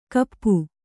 ♪ kappu